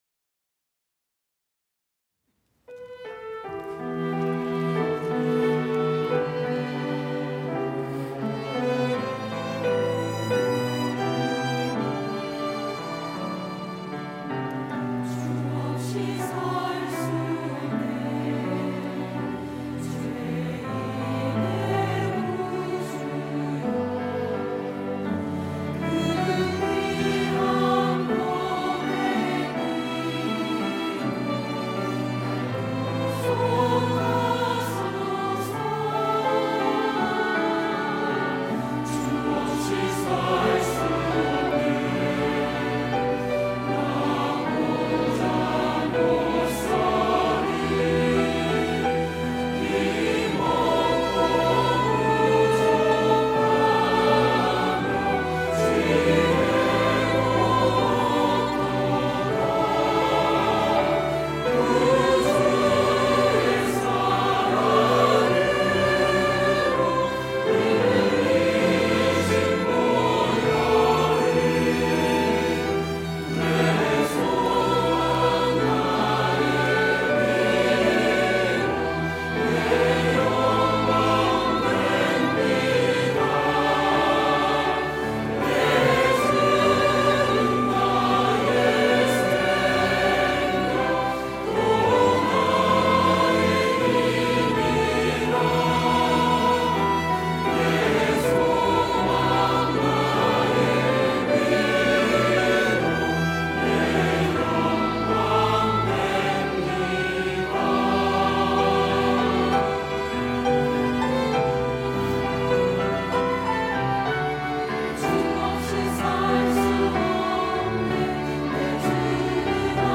호산나(주일3부) - 주 없이 살 수 없네
찬양대